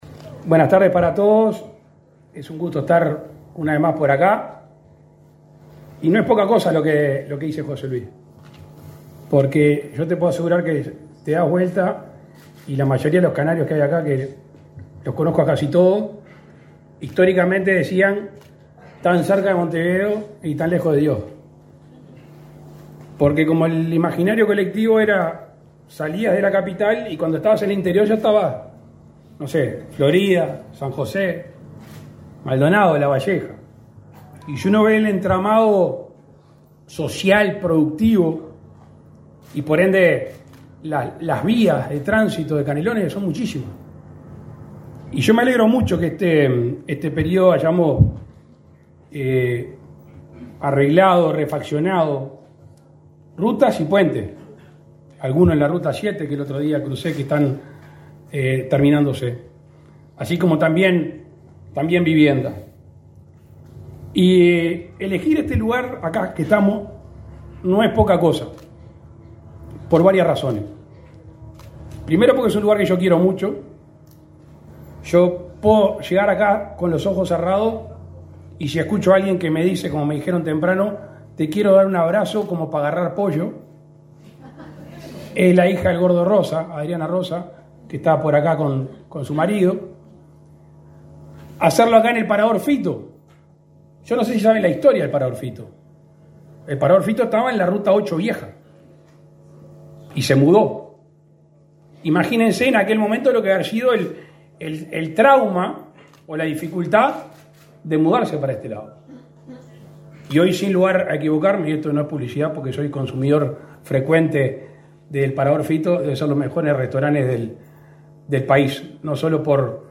Palabras del presidente de la República, Luis Lacalle Pou
Palabras del presidente de la República, Luis Lacalle Pou 17/09/2024 Compartir Facebook X Copiar enlace WhatsApp LinkedIn En el marco de la ceremonia de inauguración de obras de duplicación de vía en la ruta 8, este 17 de setiembre, se expresó el presidente de la República, Luis Lacalle Pou.